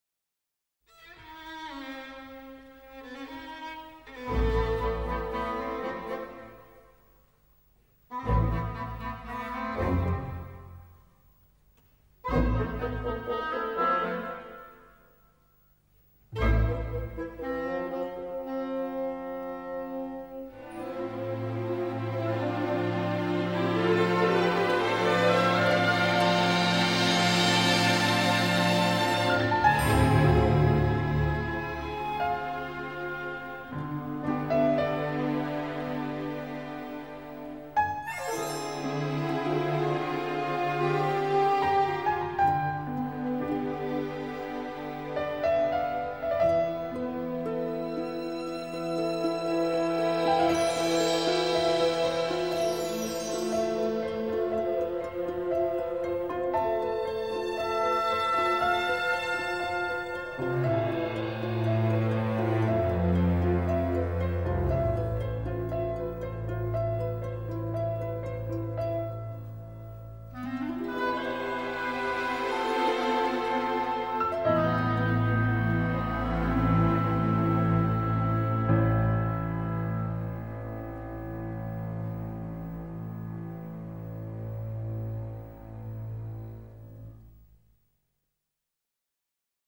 balalaika…